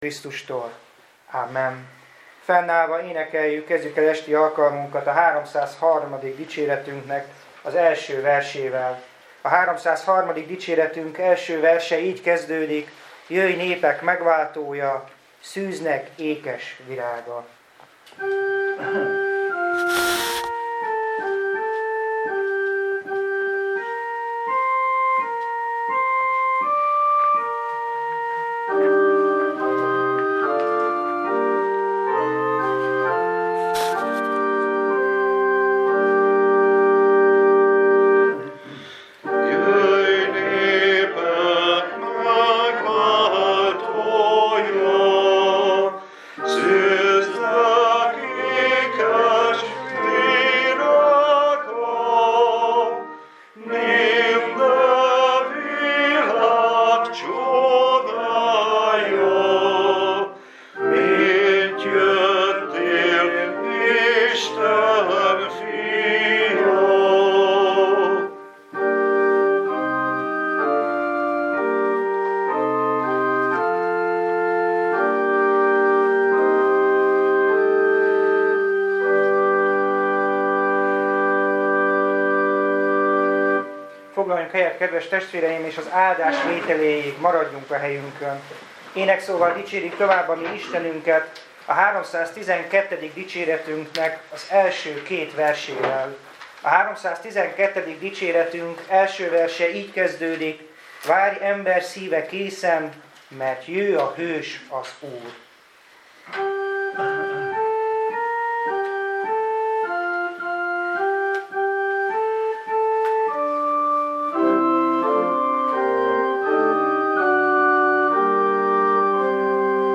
Service Type: Igehirdetés